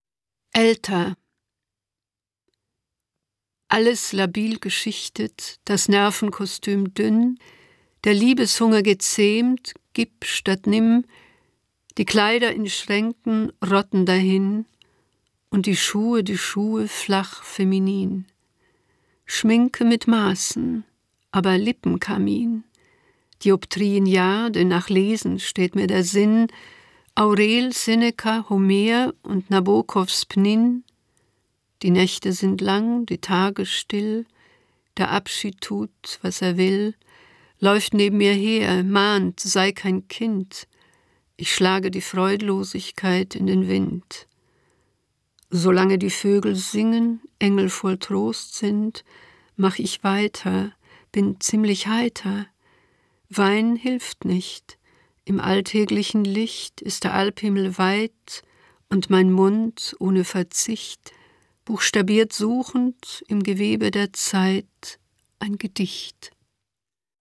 Das radio3-Gedicht der Woche: Dichter von heute lesen radiophone Lyrik.
Gelesen von Ilma Rakusa.